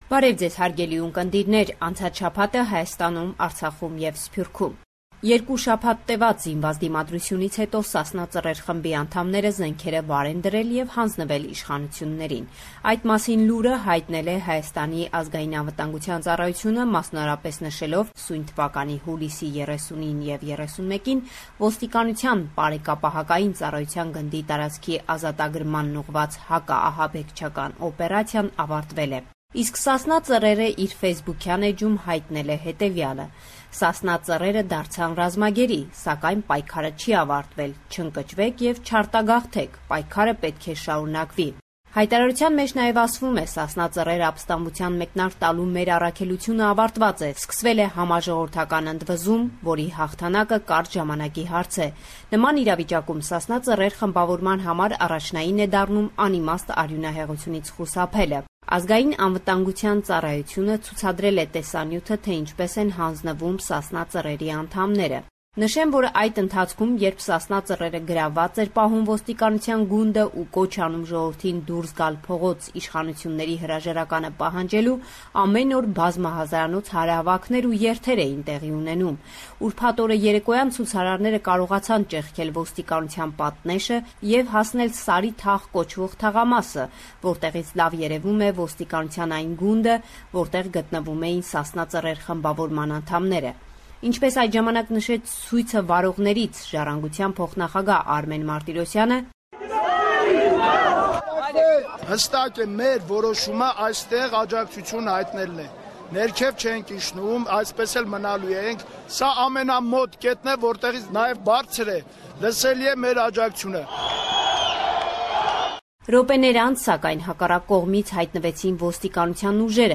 News from Armenia